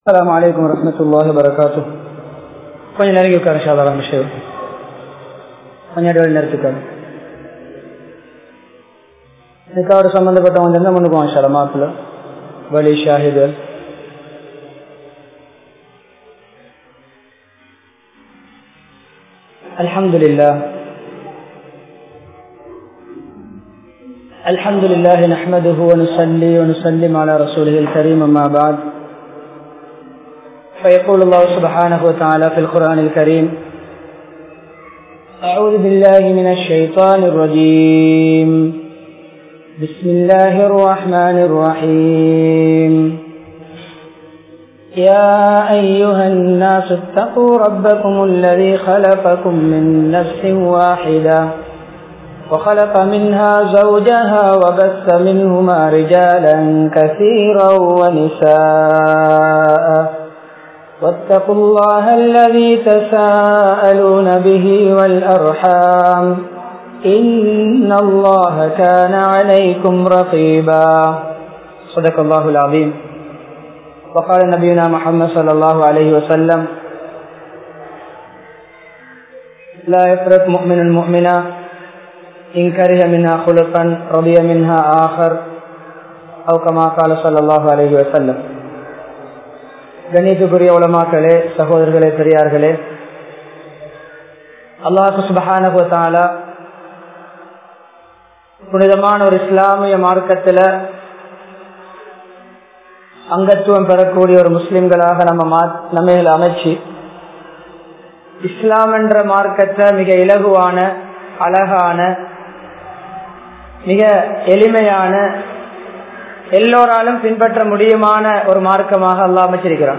Elimaiyana Thirumanam (எளிமையான திருமணம்) | Audio Bayans | All Ceylon Muslim Youth Community | Addalaichenai
Colombo 15, Kimpulahela Jumua Masjidh